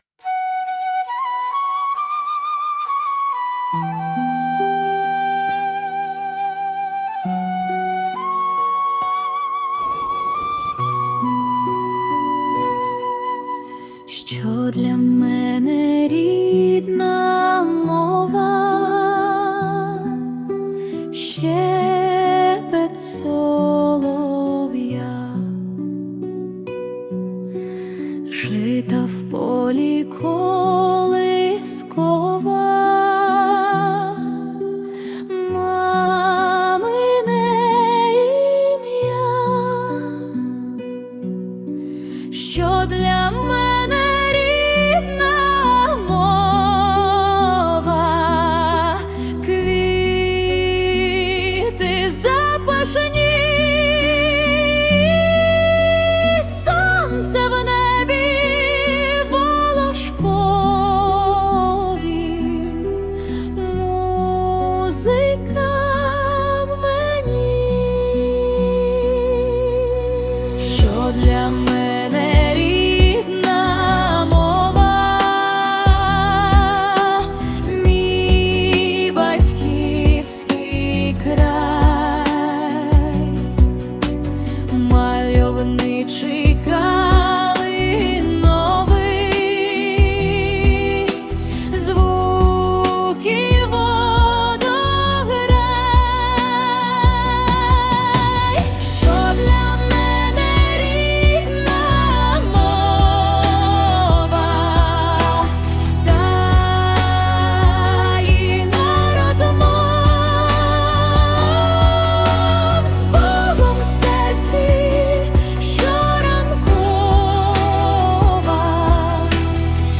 музичний супровід створено ШІ (suno)